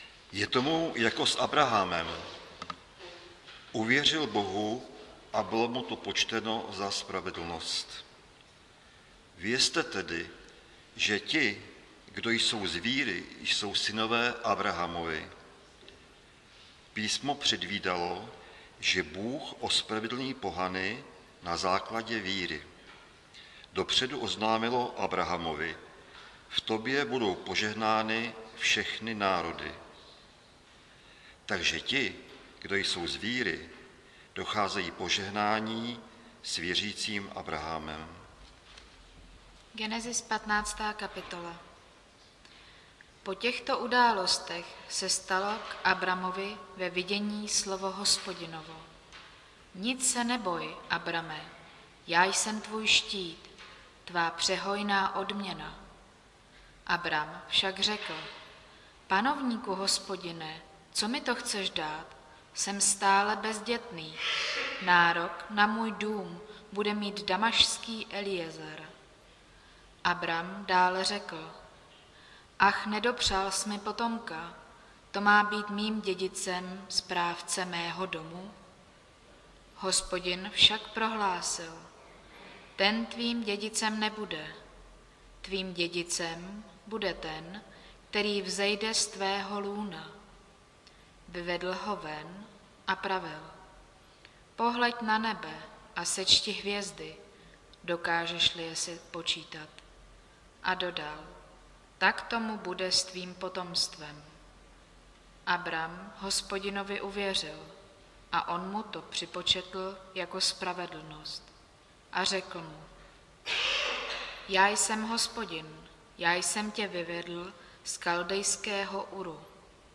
Nedělní kázání – 9.10.2022 Víra ve slib